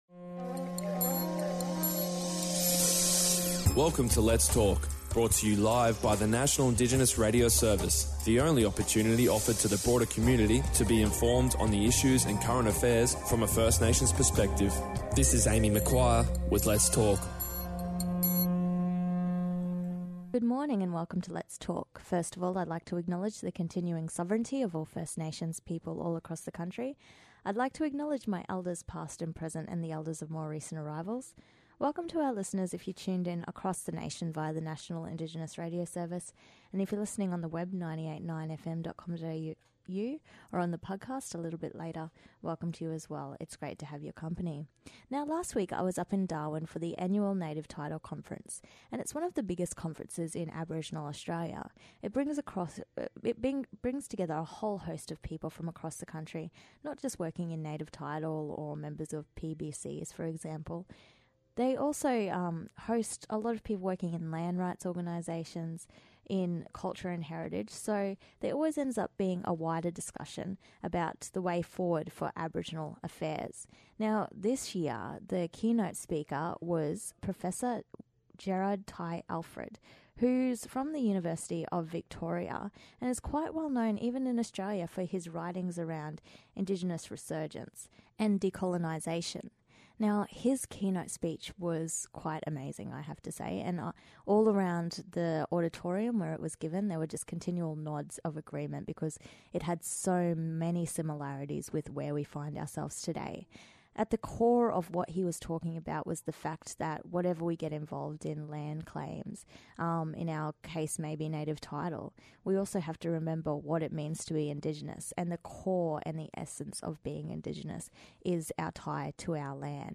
at the National Native Title Conference in Darwin to discuss Indigenous resurgence and decolonisation. We also talk about their respective nation’s experience of Treaty.